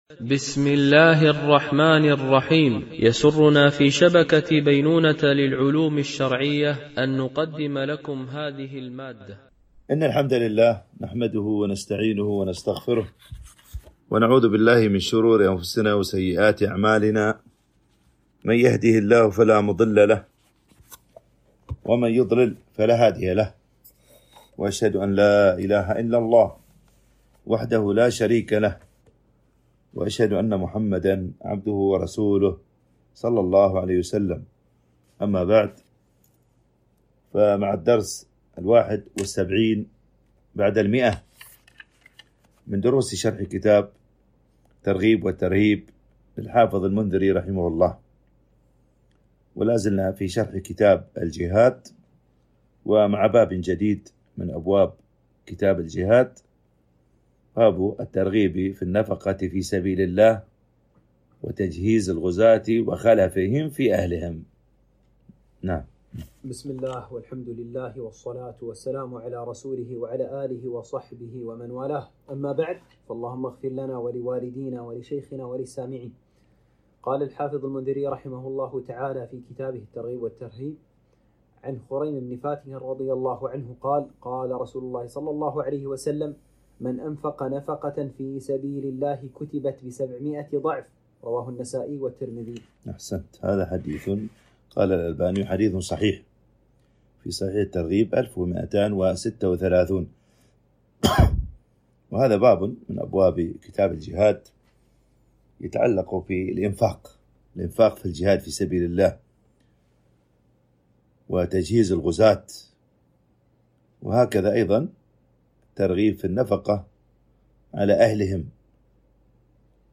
) الألبوم: شبكة بينونة للعلوم الشرعية المدة: 25:37 دقائق (11.77 م.بايت) التنسيق: MP3 Mono 44kHz 64Kbps (VBR)